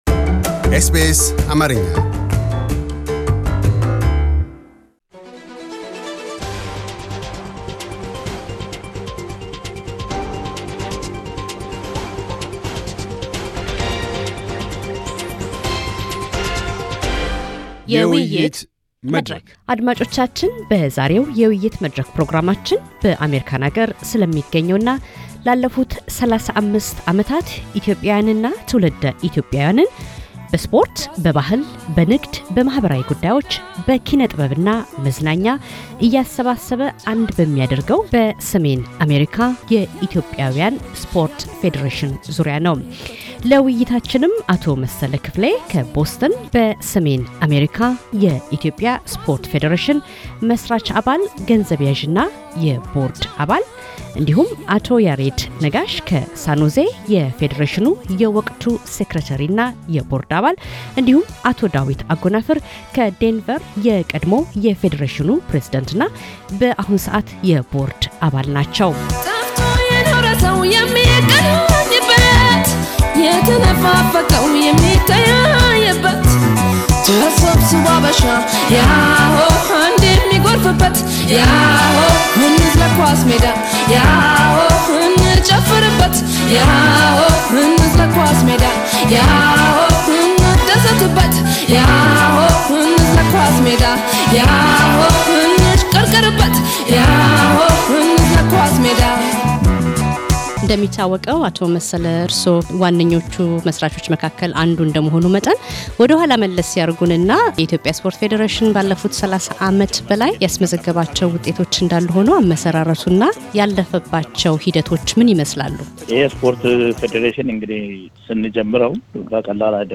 የዛሬ የውይይት መድረክ ፕሮግራማችን የትኩረት አቅጣጫም በሰሜን አሜሪካ የኢትዮጵያውያን ስፖርት ፌዴሬሽን ዙሪያ ነው።